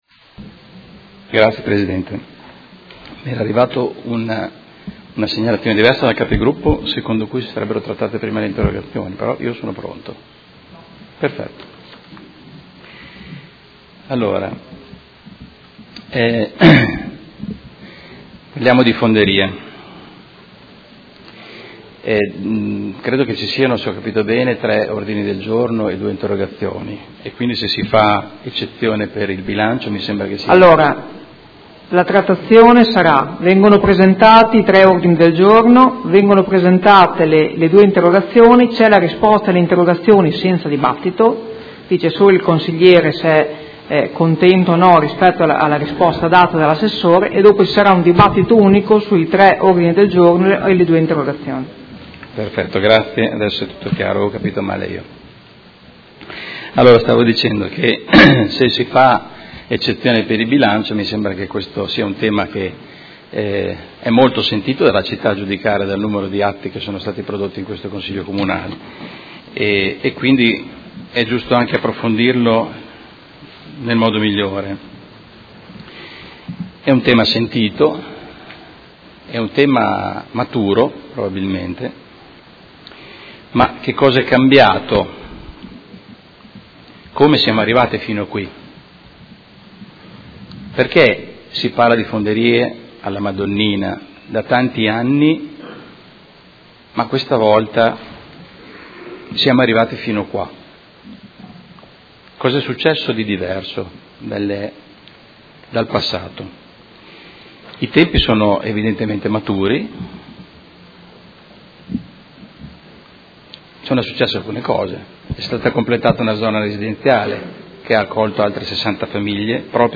Carmelo De Lillo — Sito Audio Consiglio Comunale